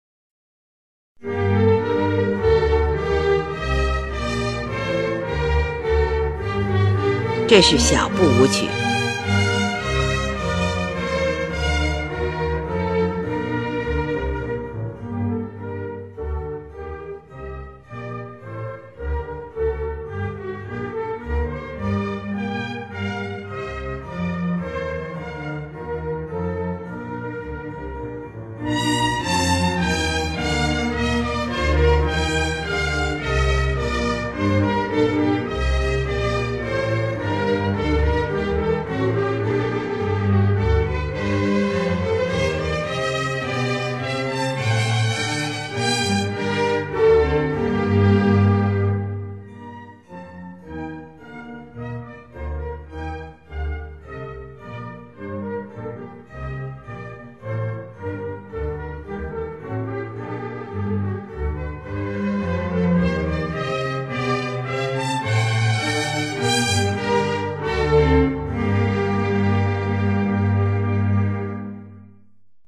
其实，这是一种源自民间的法国舞曲，三拍子节奏，常用中速演奏。
18）又是一首小步舞曲。